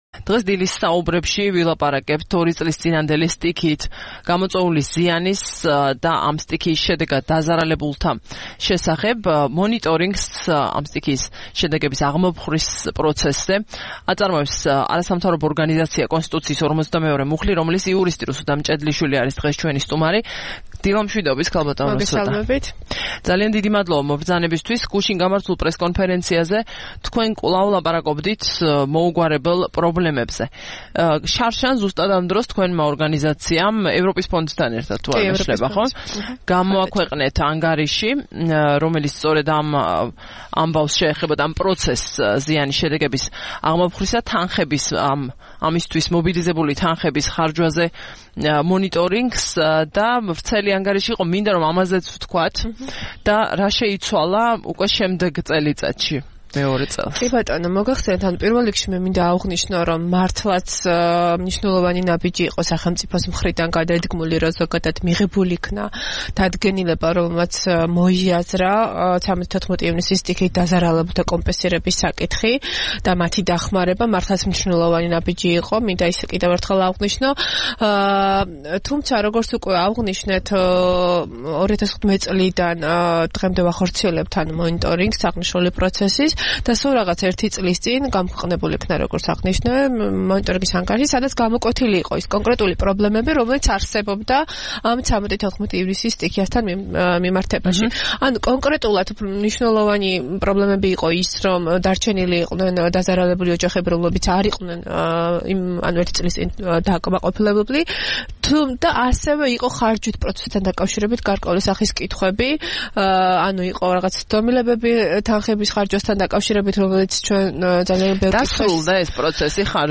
სტუმრად ჩვენს ეთერში